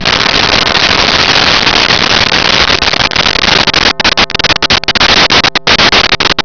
Bells007
bells007.wav